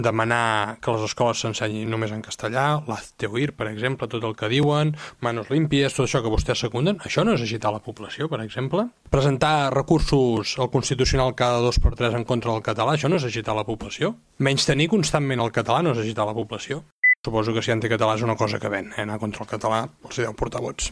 Alcalde Marc Buch: